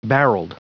Prononciation du mot barreled en anglais (fichier audio)
Prononciation du mot : barreled